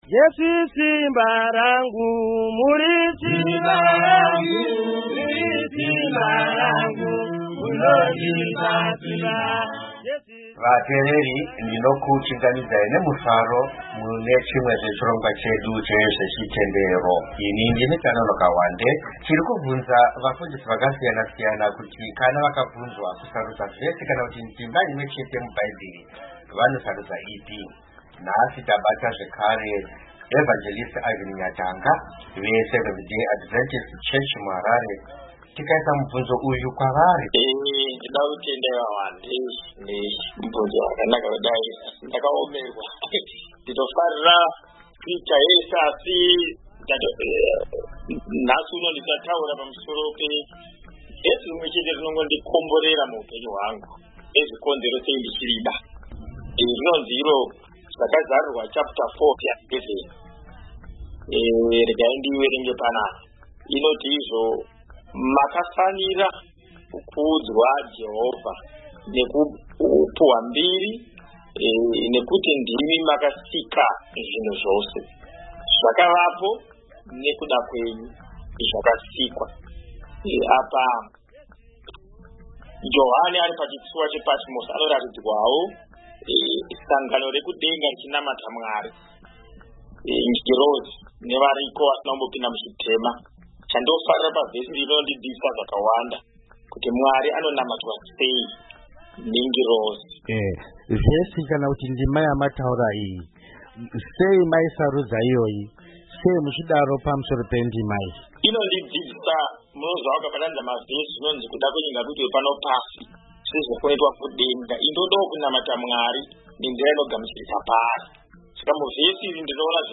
Chirongwa ChezveChitendero - Hurukuro